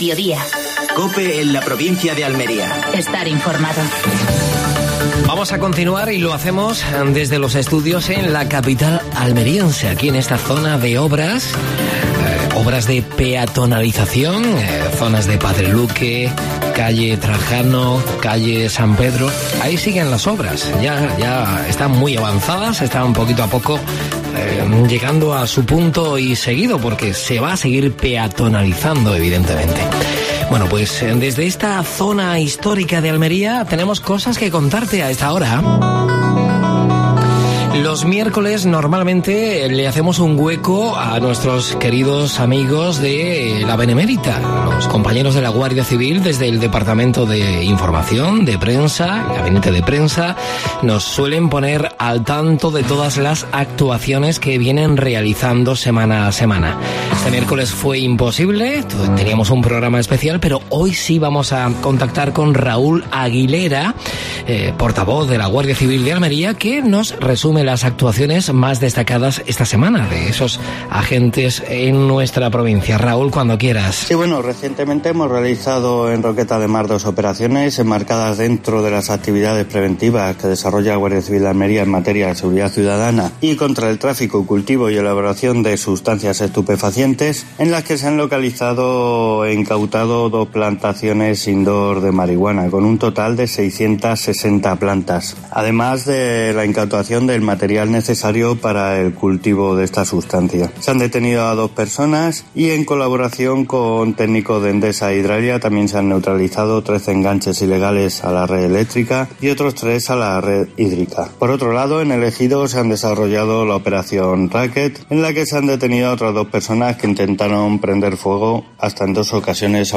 AUDIO: Actualidad en Almería. Entrevista a Margarita Cobos (concejal de Sostenibilidad Ambiental del Ayuntamiento de Almería).